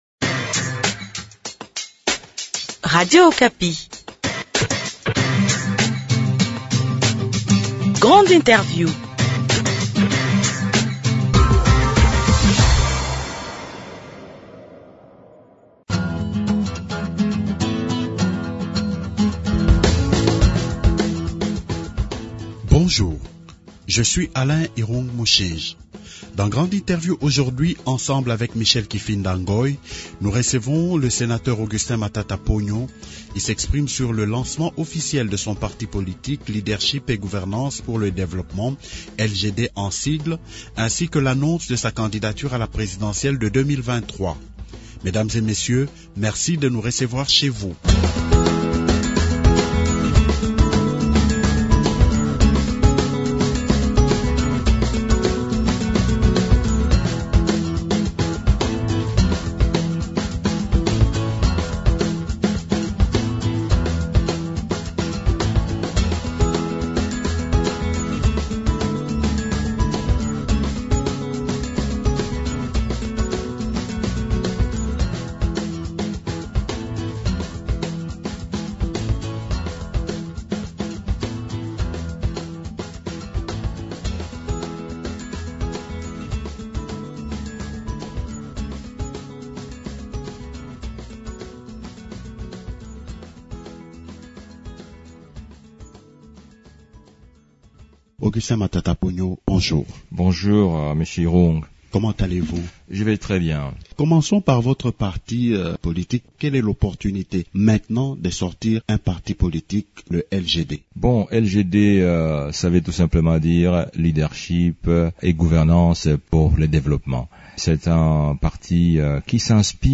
Grande Interview reçoit le sénateur Augustin Matata Ponyo. Il s’exprime sur le lancement de son parti politique Leadership et gouvernance pour le développement, LGD en sigle ainsi que sur sa candidature à la présidentielle de 2023.